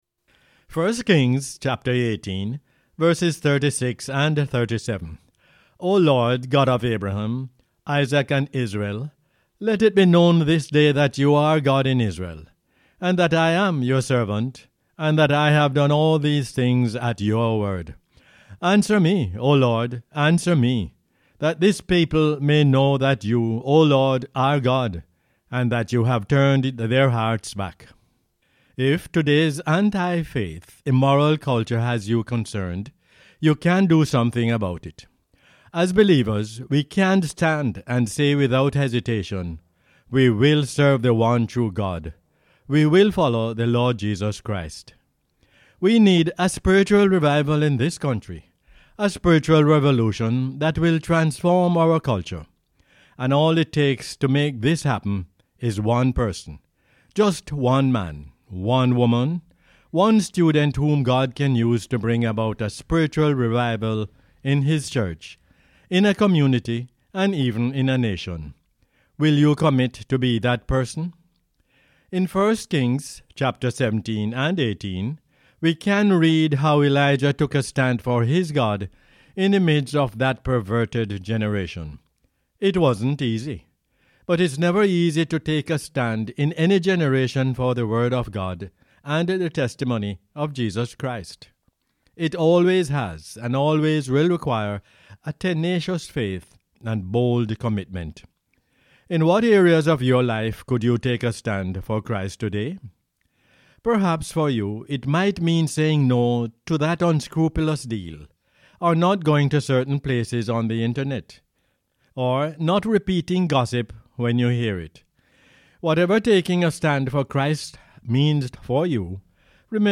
1 Kings 18:36-37 is the "Word For Jamaica" as aired on the radio on 1 November 2019.